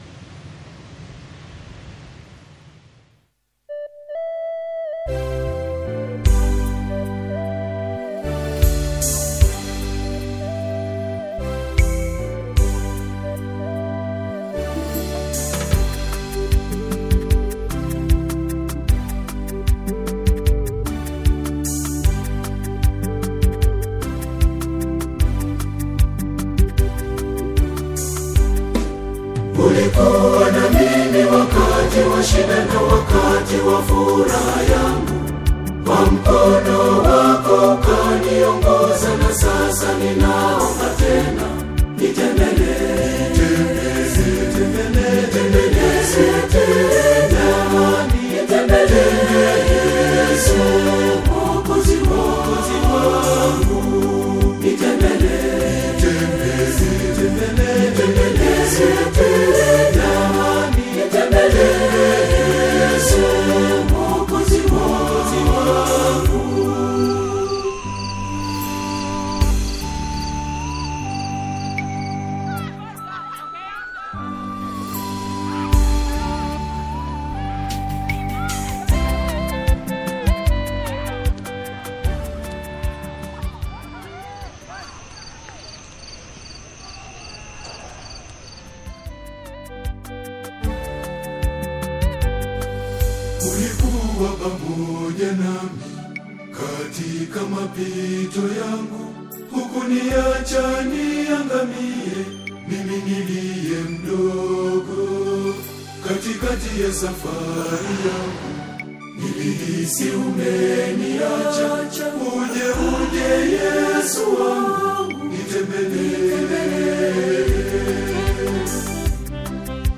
is a masterpiece of atmospheric worship.